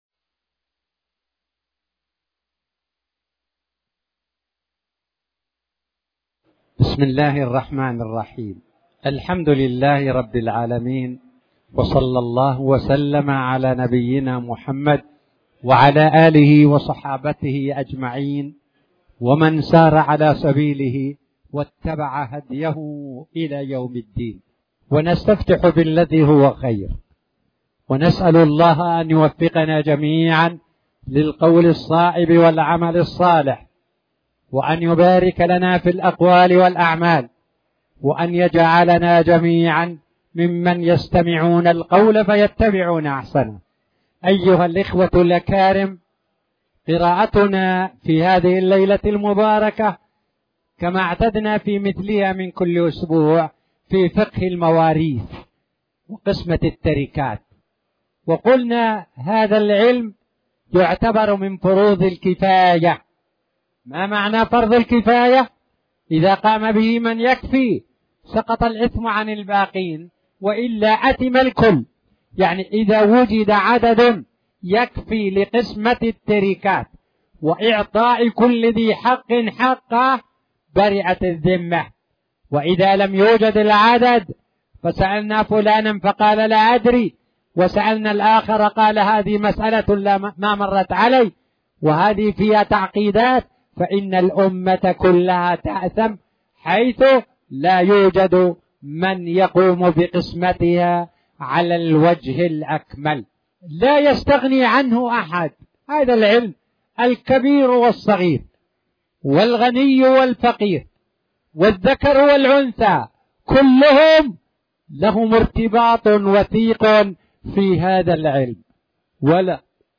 تاريخ النشر ٣٠ جمادى الأولى ١٤٣٨ هـ المكان: المسجد الحرام الشيخ